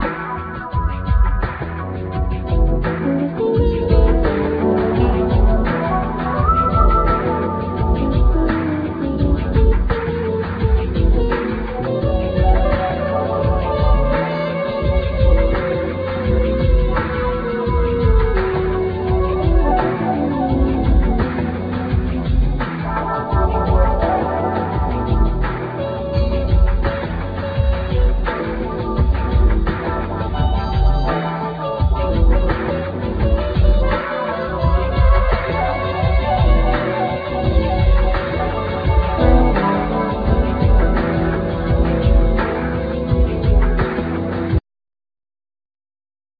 Drums
Trombone
Percussion
Tenor Sax,Clarinet
Bass
Trumpet